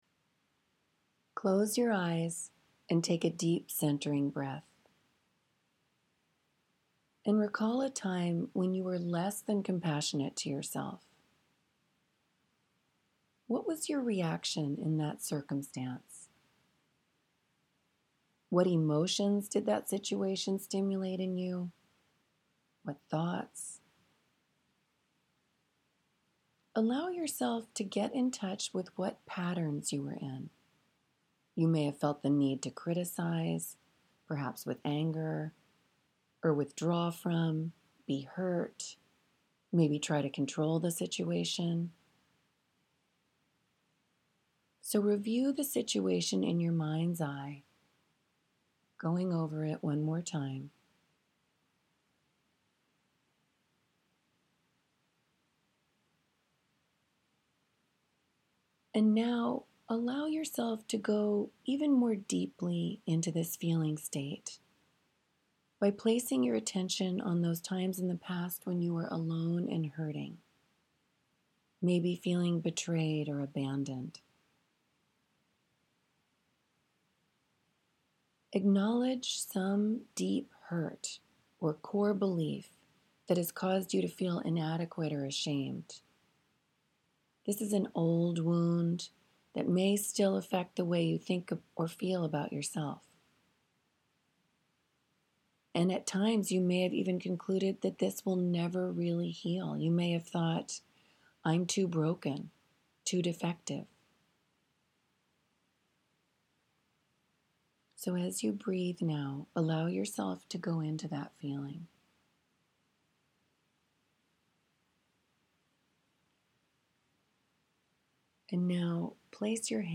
toolboxThe Hoffman Institute has a Self-Compassion visualization available for your convenience.